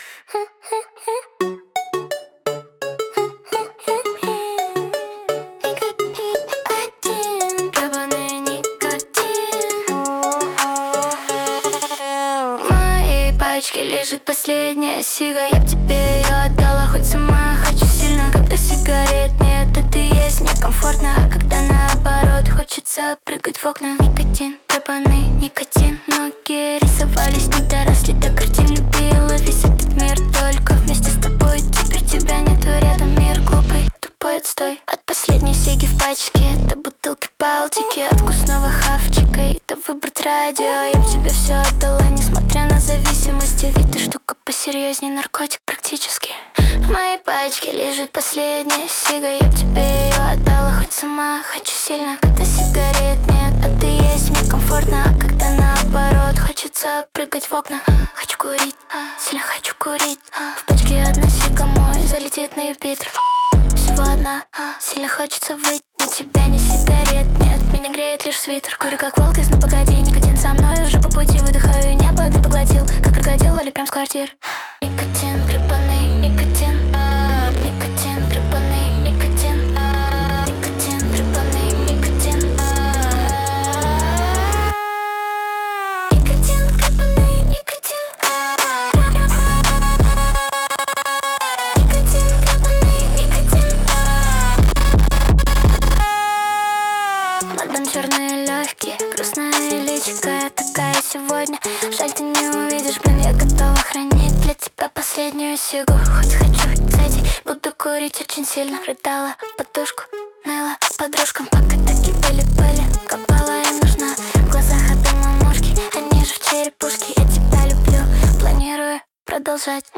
128 BPM
RnB